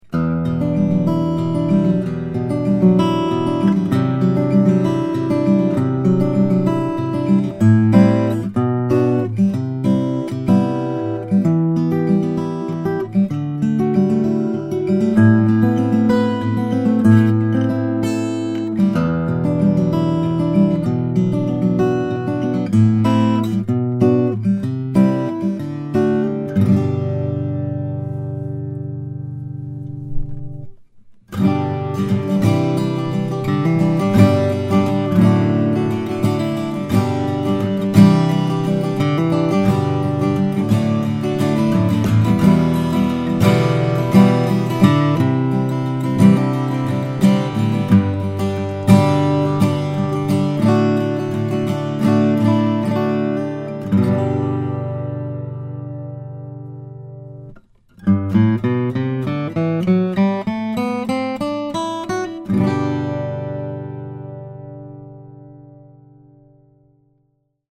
All of which adds up to a very easy playing Collings OM with lovely appointments and serious tone.